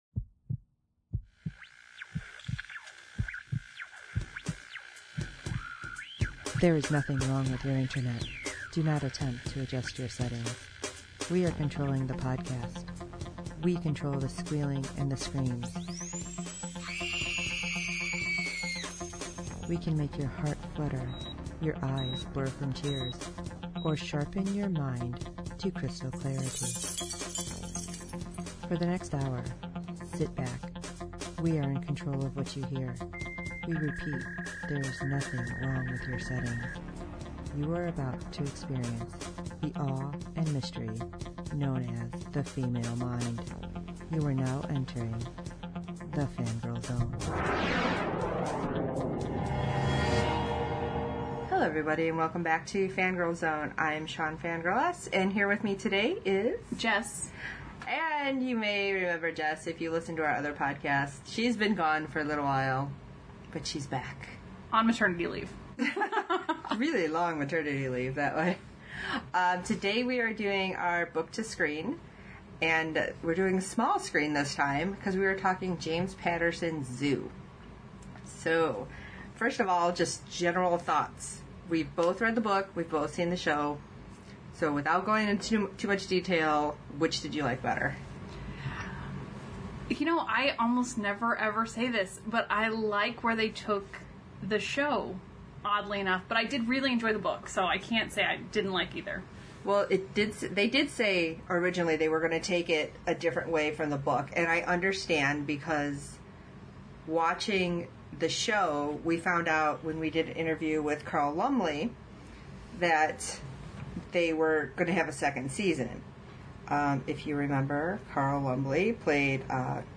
Our host invite guests on to discuss how well a book was translated to the big and small screens